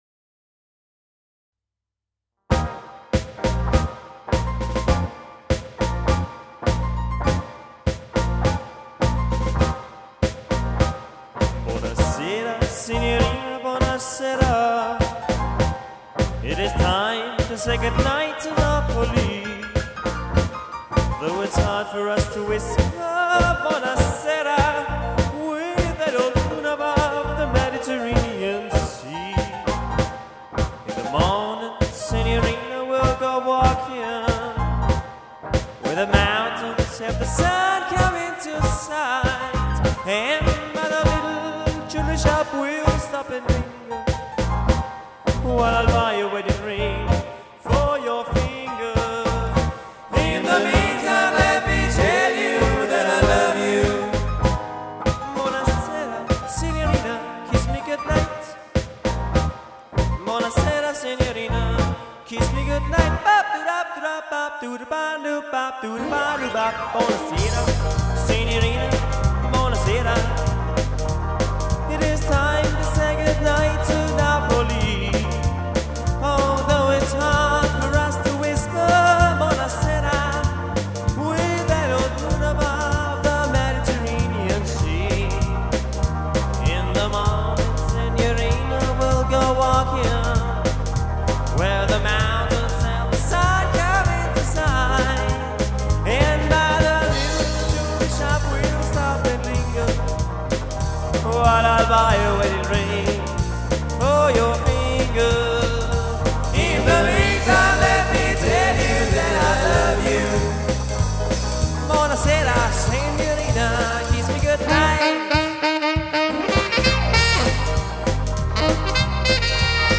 4 Musiker